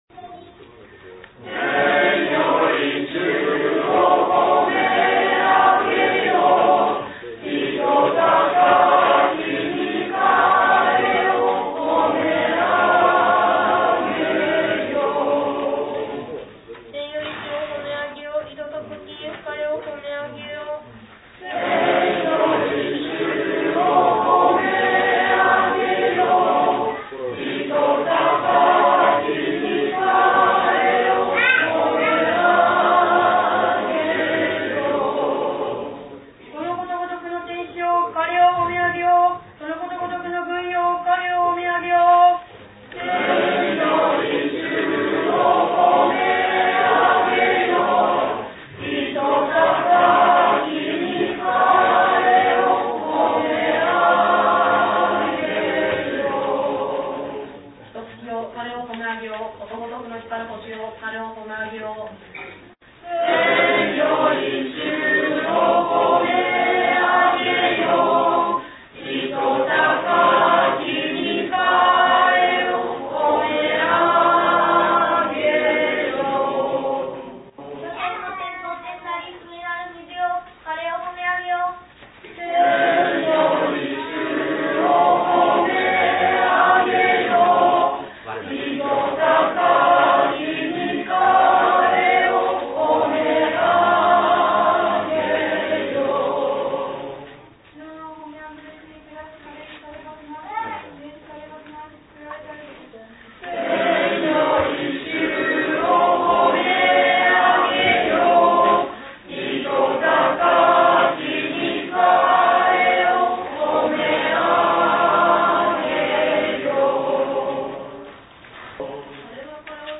新しい聖堂は天井が高く、堂内に木を多用してあるため、柔らかく響きます。
We have been advocating congregational singing, now almost all the attendants participate singing.
○聖体礼儀から　　Live recording at Divine Liturgy on Aug. 21　NEW
Children read the psalm verses and the whole congregation sing the simple refrain, following the Byzantine Cathedral rite tradition.